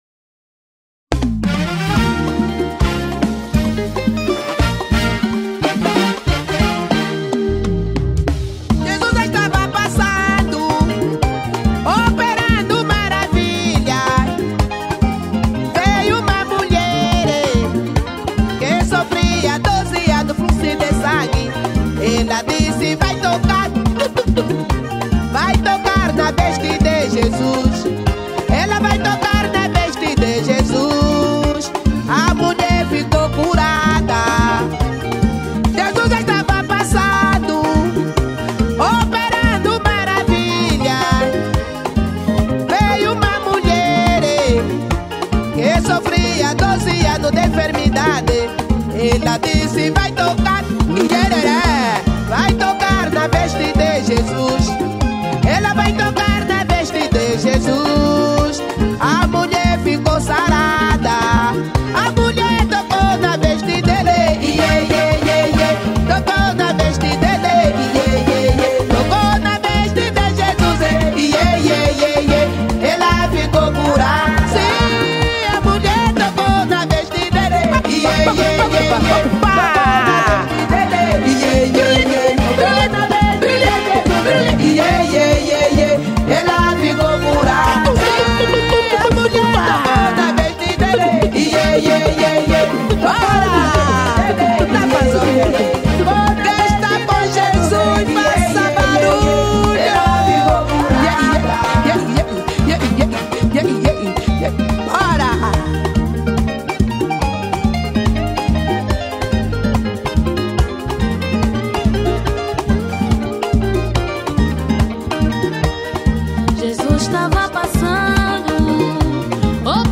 Gospel 2025